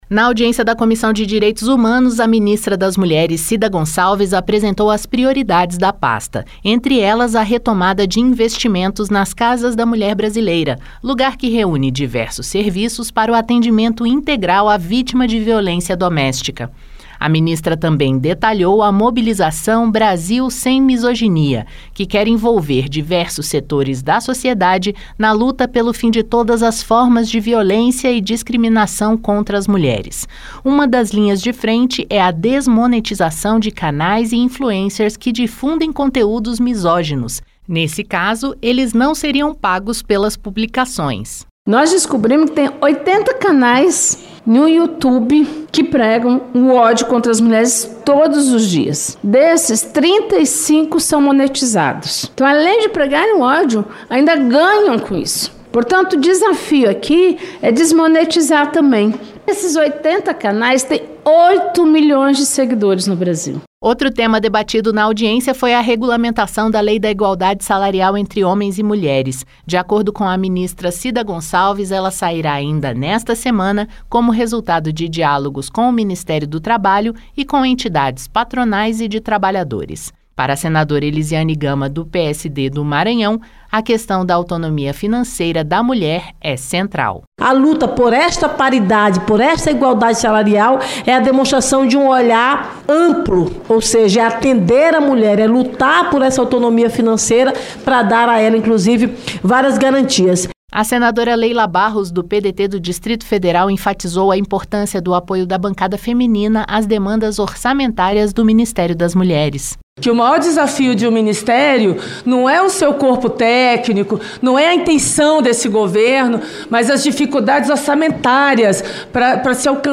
Em audiência na Comissão de Direitos Humanos, a ministra das Mulheres, Cida Gonçalves, detalhou as prioridades, entre elas, a retomada de investimento nas Casas da Mulher Brasileira e a regulamentação da lei da igualdade salarial, anunciada para esta semana.